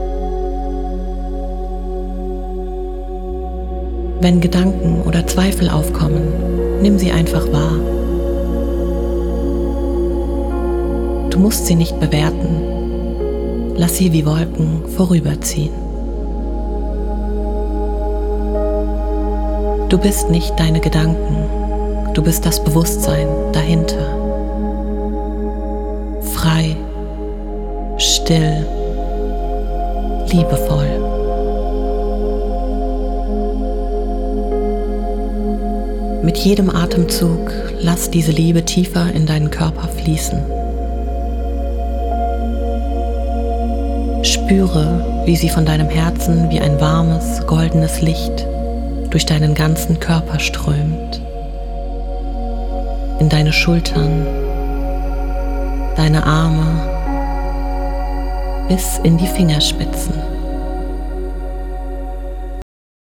Eine sanfte Meditation, die dich lehrt, dich selbst zu lieben und anzunehmen.
Frequenz: 528 Hz – Fördert Selbstliebe und Heilung.
8D-Musik: Umhüllt dich mit sanften Klängen, verstärkt das Gefühl der inneren Harmonie.